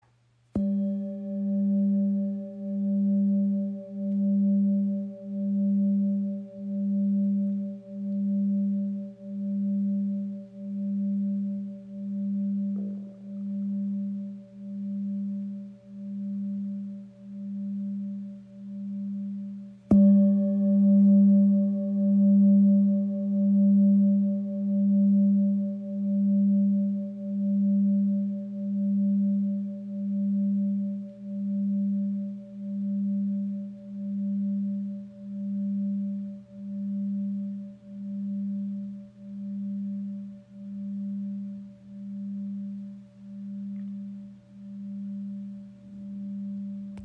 Tibetische Klangschale mit Blume des Lebens | ø 21 cm | Ton ~ G
Handgefertigte Klangschale aus Nepal, mit eingravierter Blume des Lebens
• Icon Zentrierender, obertonreicher Klang im Ton ~ G (Halschakra)
Ihr obertonreicher Klang im Ton ~ G ist klar und erdend und kann auf das Halschakra aktivierend wirken.